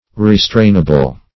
Search Result for " restrainable" : The Collaborative International Dictionary of English v.0.48: Restrainable \Re*strain"a*ble\, a. Capable of being restrained; controllable.